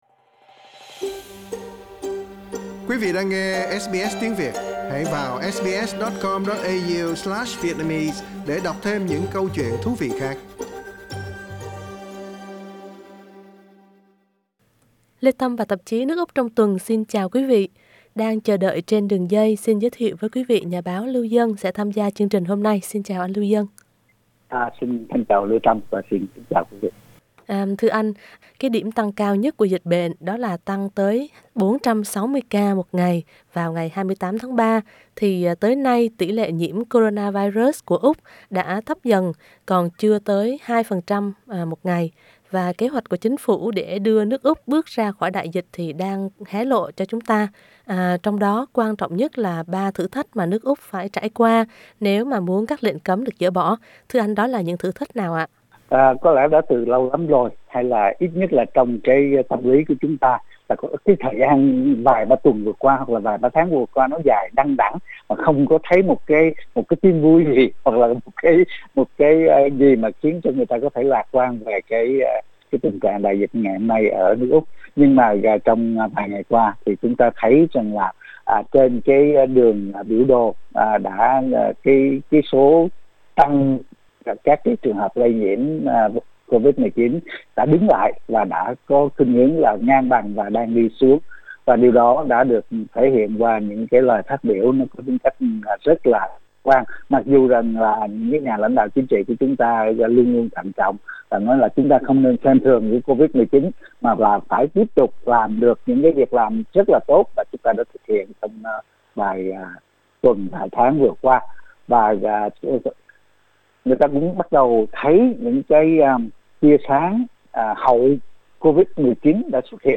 Xin mời quý vị bấm vào hình trên để nghe cuộc hội thoại.